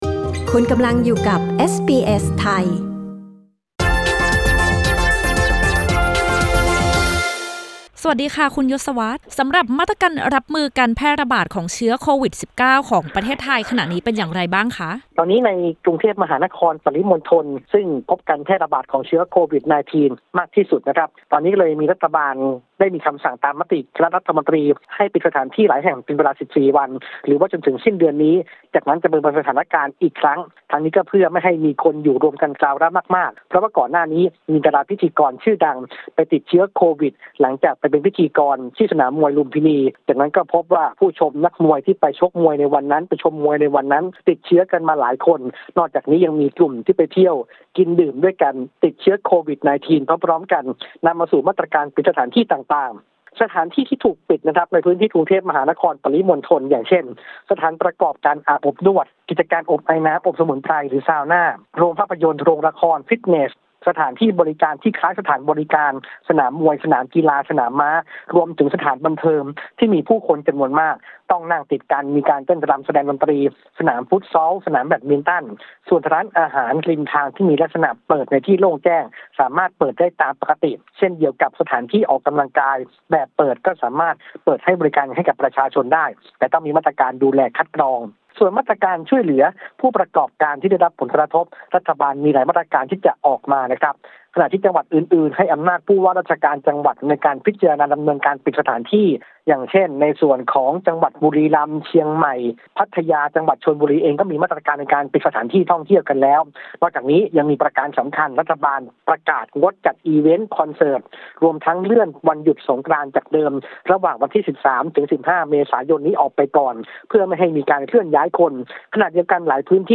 รายงานข่าวสายตรงจากเมืองไทย 19 มี.ค.